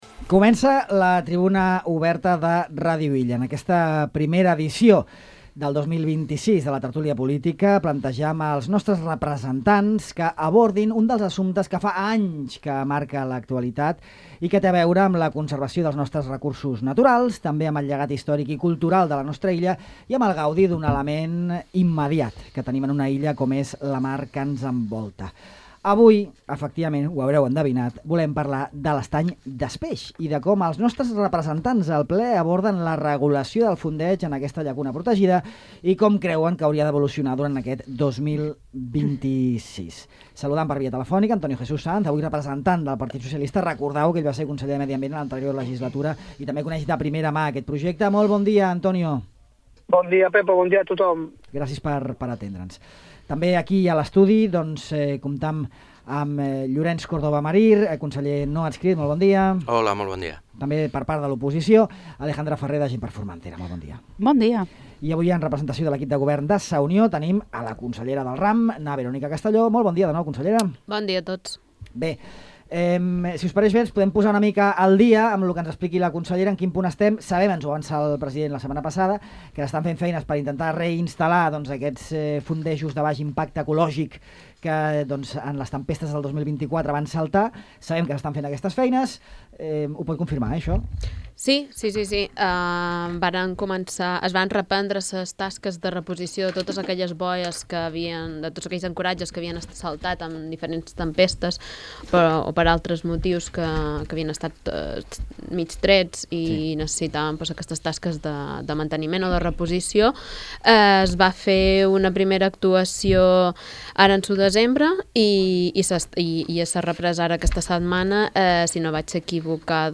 Tertúlia política: el fondeig a l'estany des Peix